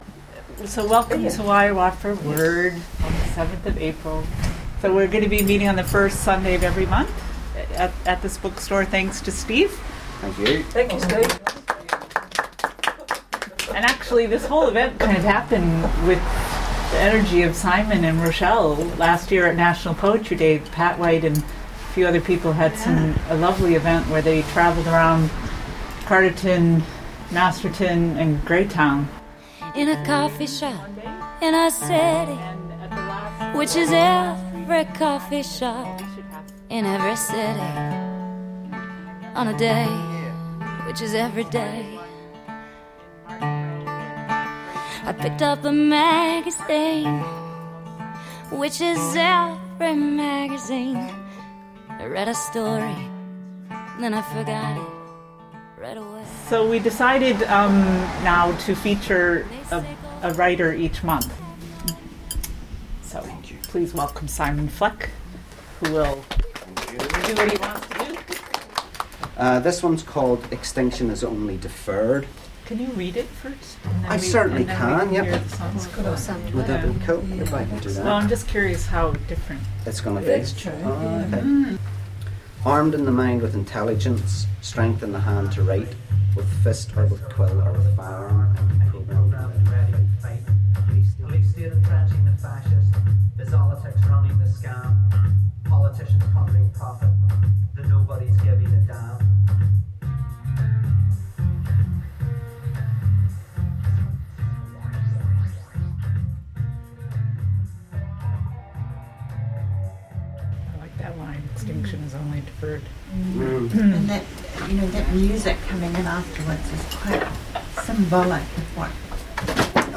A glimpse at April’s Wairarapa Word open mic event…
Well, admittedly the levels are all over the show, and overall my editorial cobbling together could do with more…. cobbling!  Nevertheless, below is my attempt at a 5 minute glimpse at the most recent Wairarapa Word open mic event held on 7th April 2013 at Almos Bookstore in Carterton.